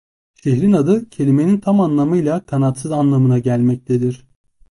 Pronúnciase como (IPA) /ka.natˈsɯz/